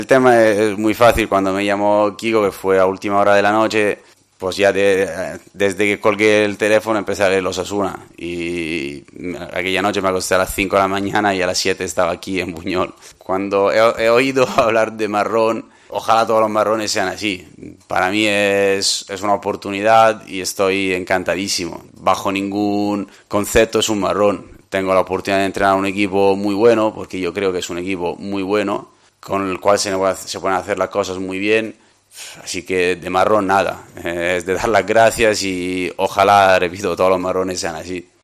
“Cuando he oído hablar de marrón… Ojalá todos los marrones sean así, es una oportunidad y estoy encantadísimo. Bajo ningún concepto es un marrón, tengo la oportunidad de entrenar a un equipo muy bueno, con el que se pueden hacer las cosas bien”, dijo en la rueda de prensa previa al partido de su debut en Melilla.